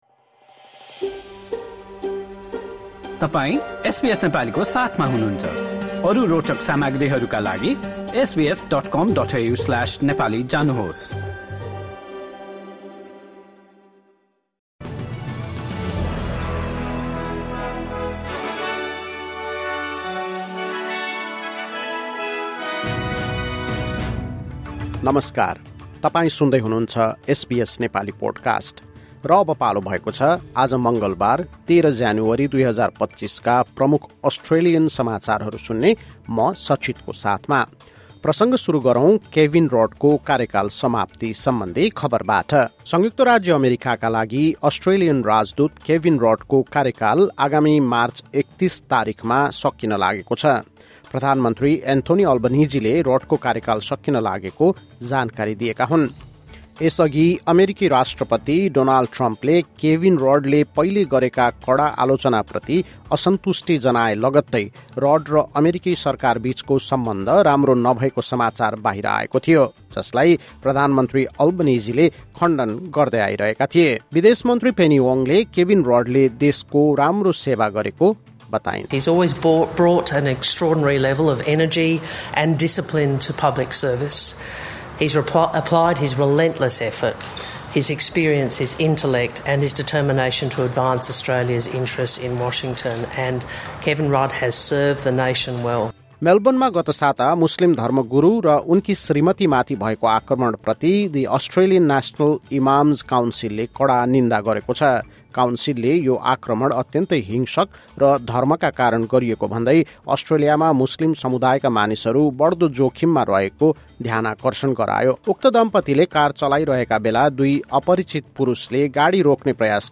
SBS Nepali Australian News Headlines: Tuesday, 13 January 2026